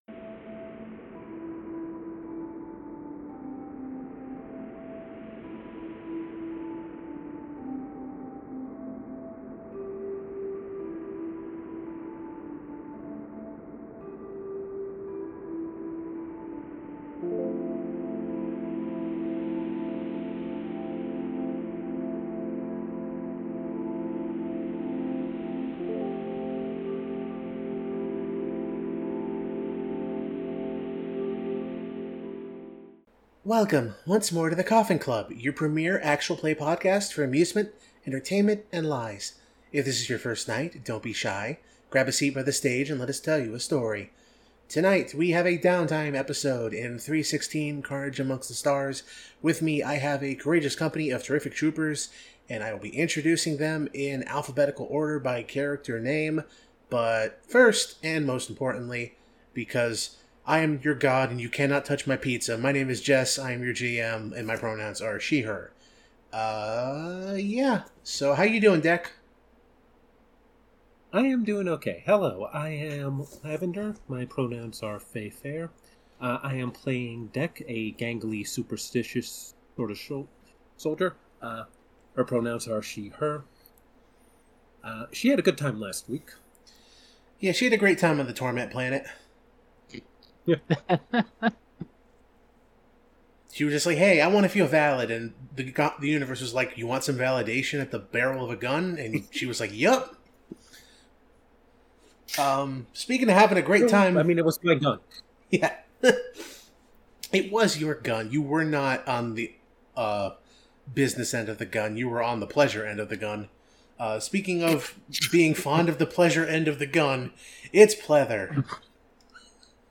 The Coffin Club is an actual-play podcast of friends recording their games to share with others. We play the games we want to play, tell the stories we want to tell and see what emerges in the process of collaboration.